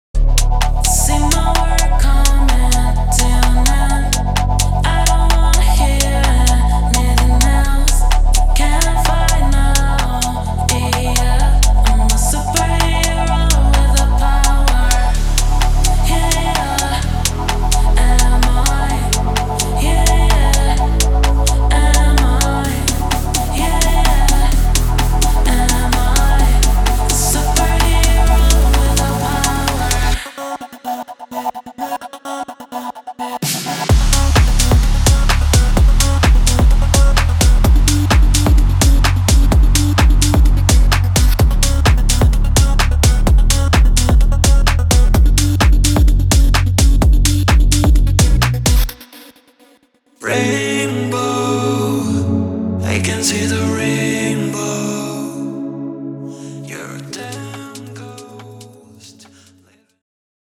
• 3 Acapella Vocals Dry
• 20 Drum Loops
• 40 Instrumental Loops